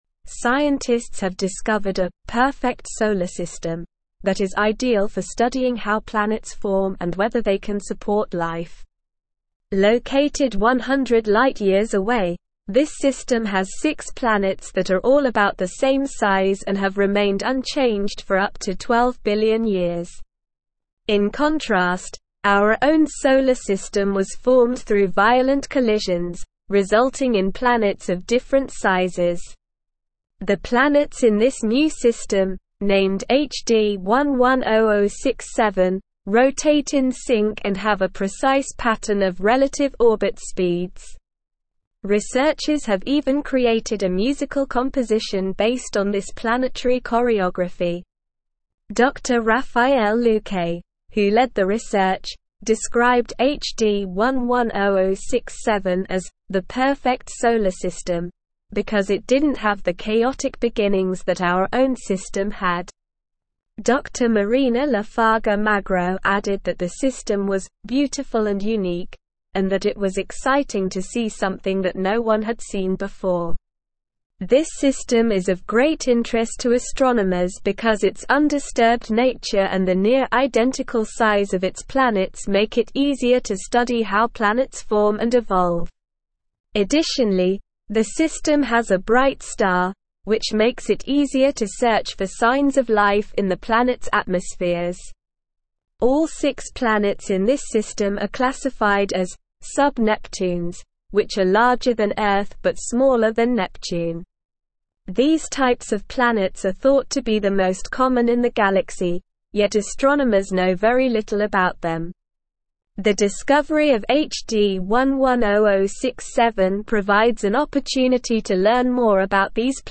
Slow
English-Newsroom-Advanced-SLOW-Reading-Perfect-Solar-System-Potential-for-Life-and-Discovery.mp3